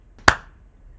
clap-detection
clap-05_volume0.wav